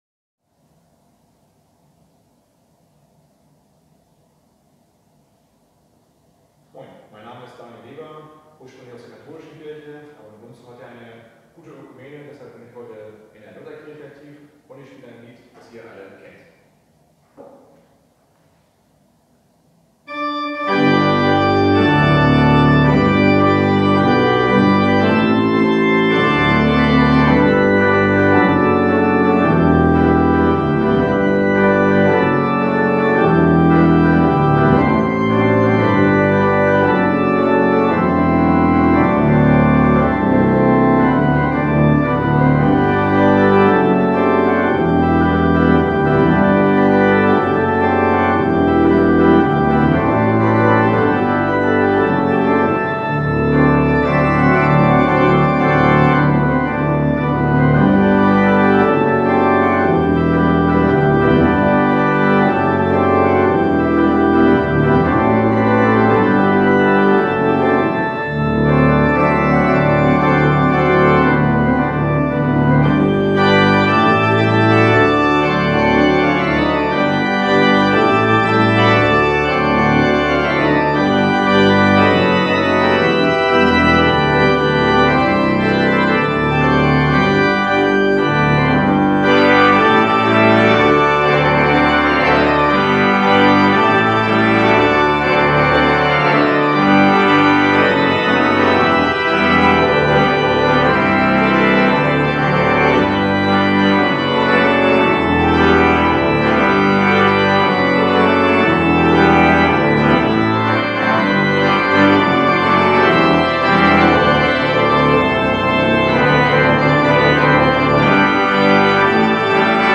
liebessatten Osterfanfare